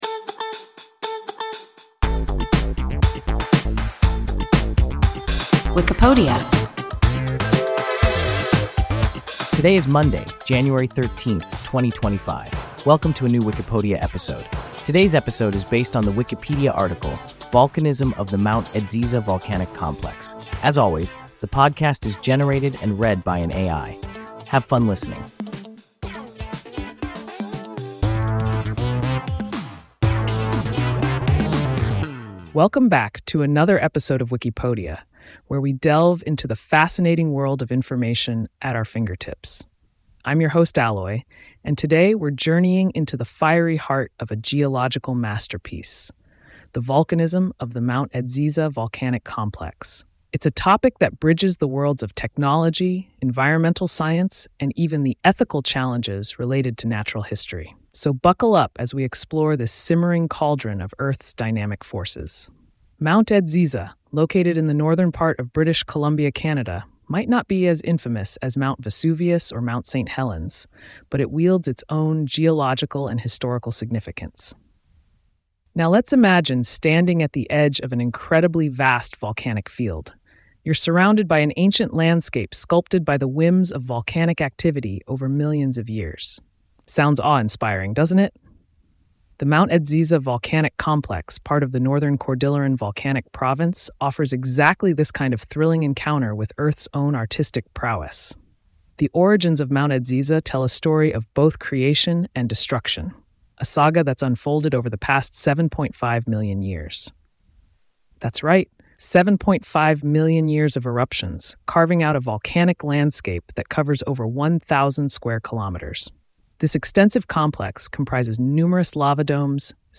Volcanism of the Mount Edziza volcanic complex – WIKIPODIA – ein KI Podcast